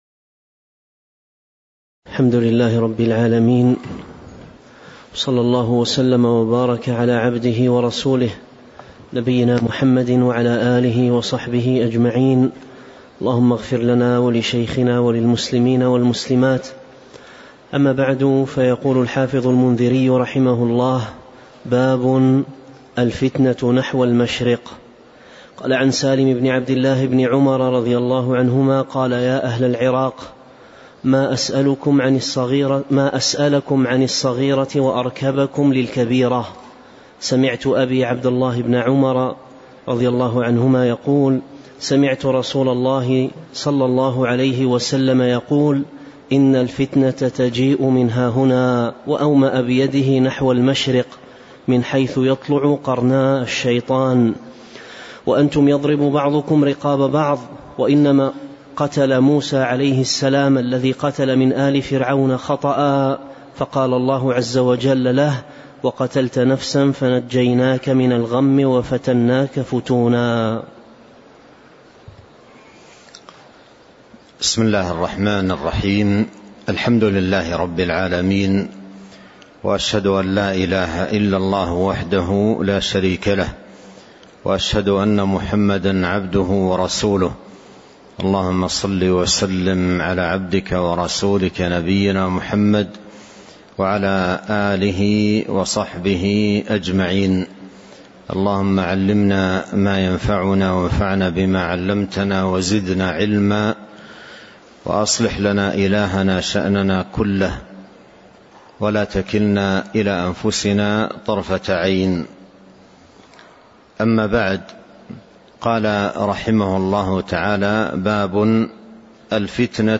تاريخ النشر ٤ صفر ١٤٤٤ هـ المكان: المسجد النبوي الشيخ